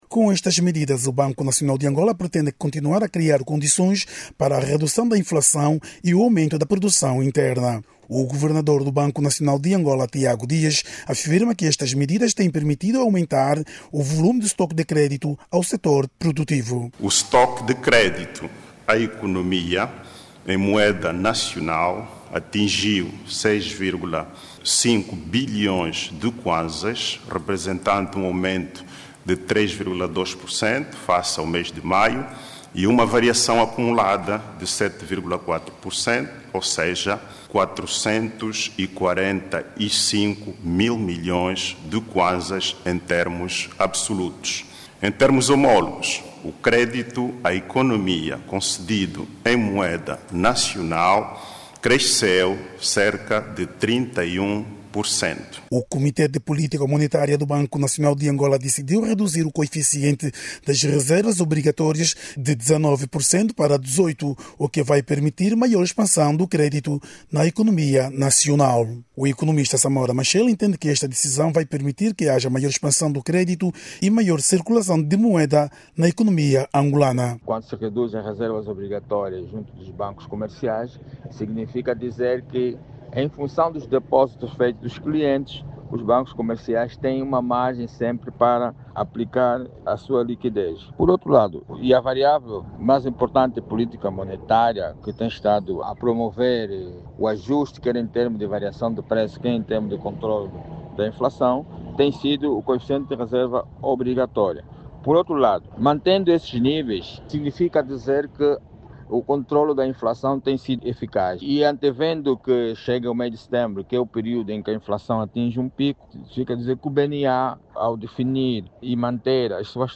O Comité de Política Monetária do BNA, vai reduzir o coeficiente das reservas obrigatórias dos bancos comerciais em moeda nacional de 19 para 18 por cento. A decisão, saiu da última reunião do Comité de Politica Monetária do BNA e, vai estimular mais a produção interna. Clique no áudio abaixo e ouça a reportagem